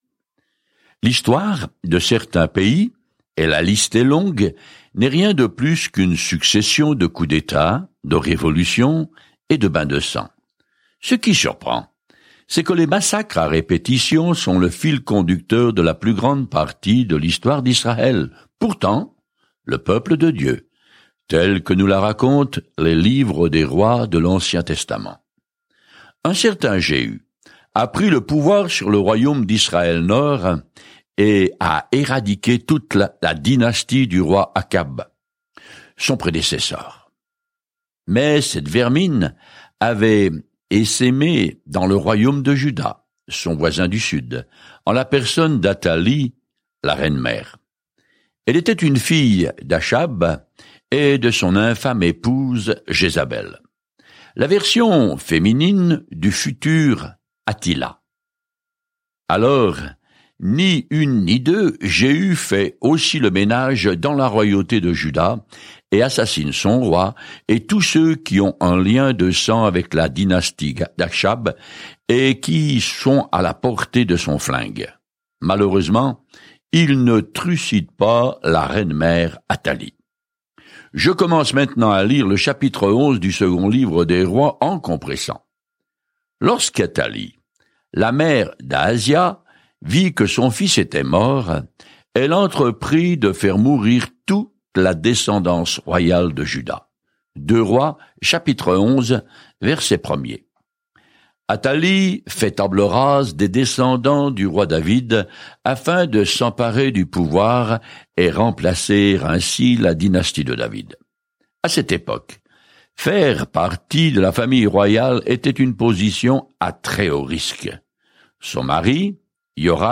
Écritures 2 Rois 10:30-36 2 Rois 11 2 Rois 12 2 Rois 13:1-18 Jour 6 Commencer ce plan Jour 8 À propos de ce plan Le livre des Seconds Rois raconte comment les gens ont perdu de vue Dieu et comment il ne les a jamais oubliés. Parcourez quotidiennement 2 Rois en écoutant l’étude audio et en lisant certains versets de la parole de Dieu.